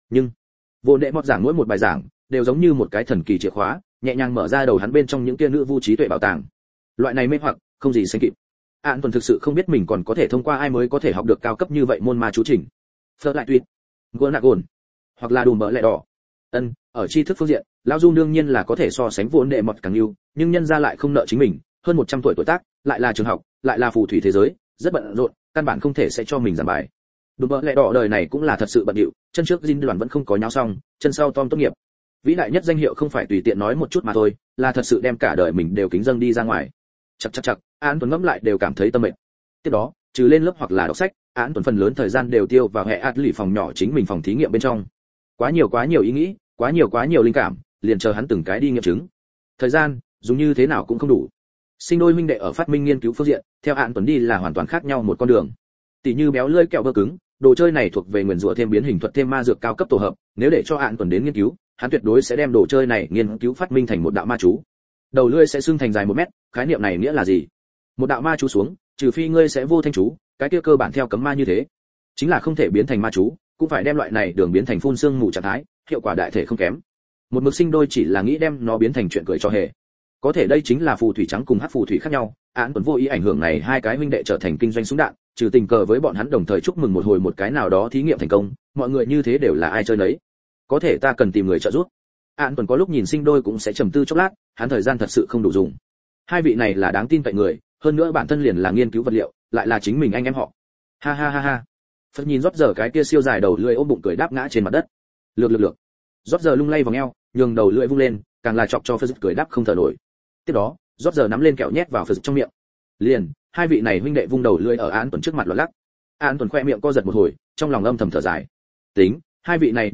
Hogwarts Chi Phù Thủy Xám Audio - Nghe đọc Truyện Audio Online Hay Trên AUDIO TRUYỆN FULL